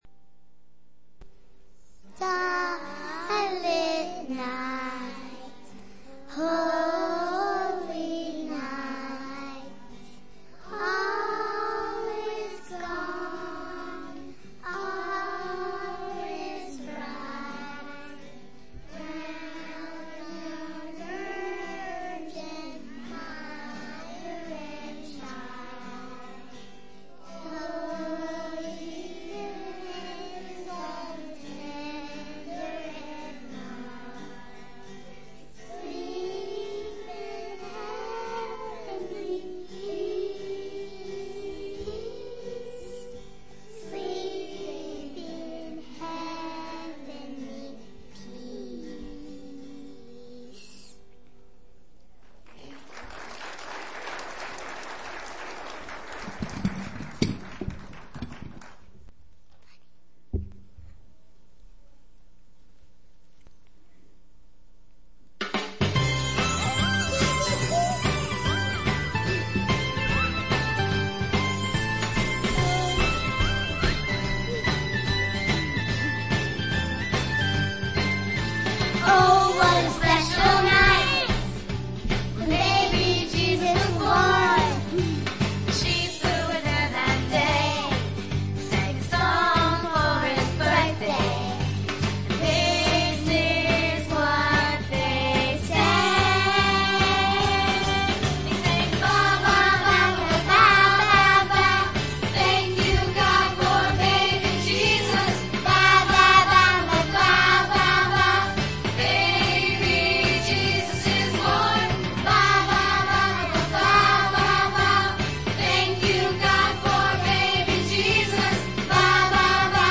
Preacher: Children’s Choirs
Christmas Musical